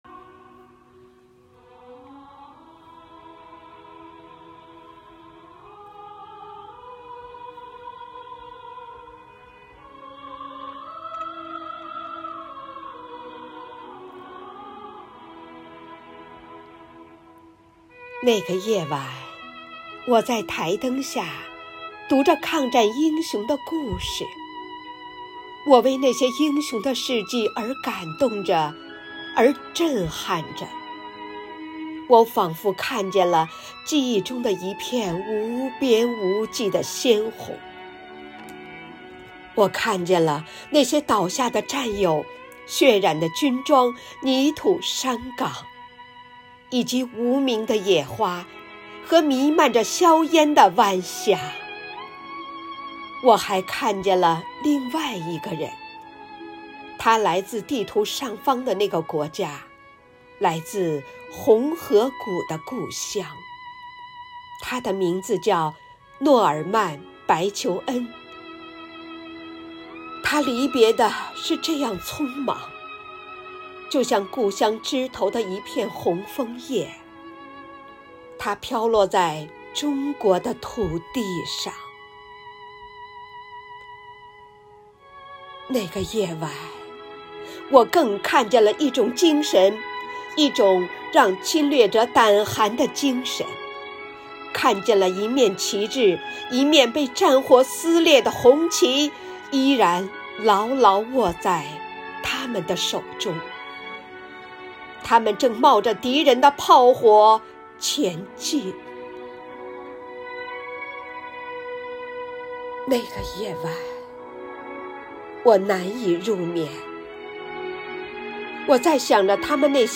独诵